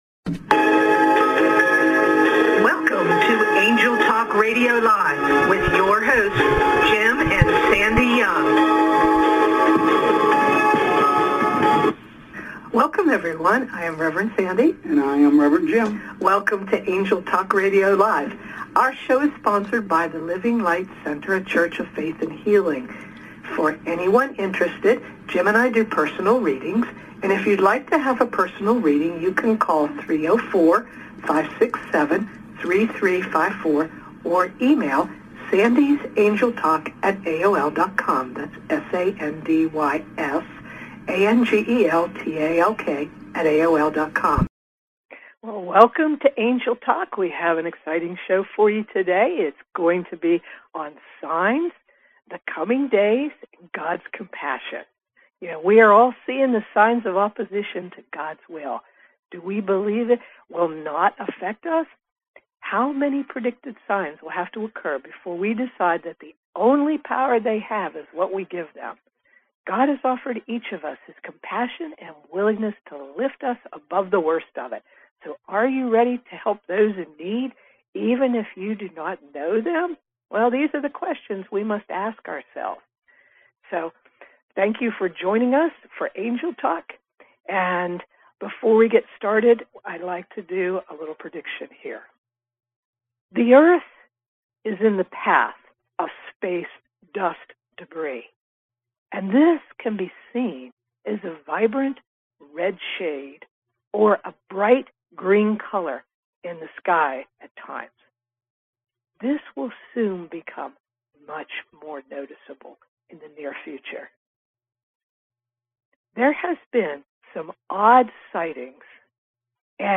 We invite you to join us on Angel Talk for another exciting radio show.